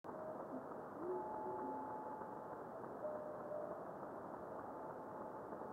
video and stereo sound:
Minimal reflection.
Radio spectrogram of the time of the above meteor.  61.250 MHz reception above white line, 83.250 MHz below white line.